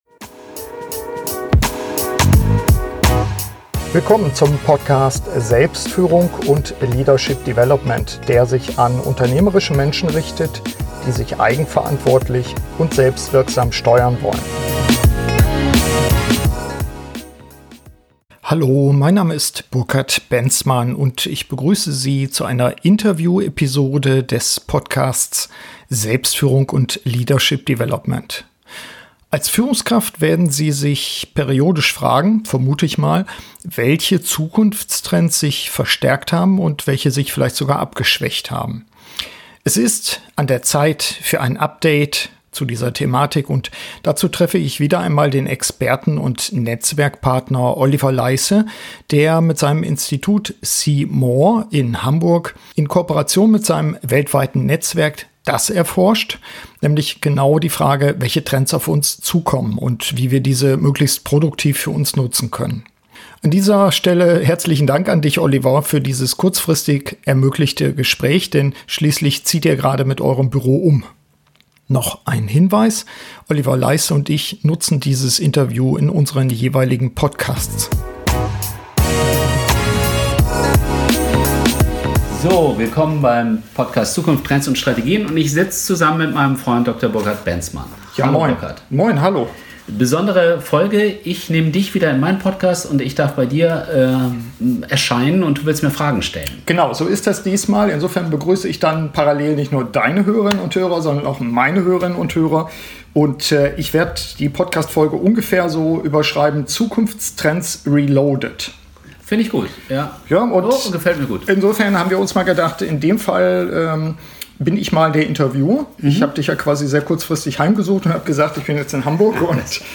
SF83 Zukunftstrends reloaded – Gespräch